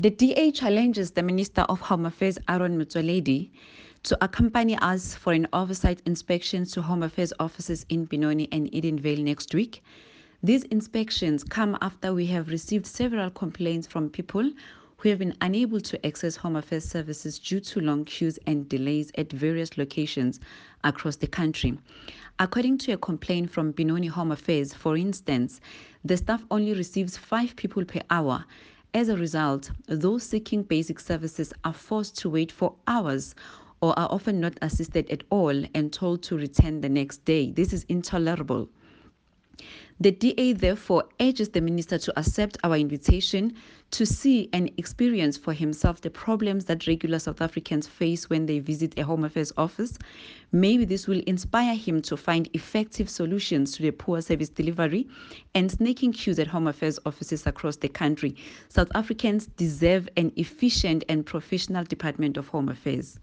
soundbite by Angel Khanyile MP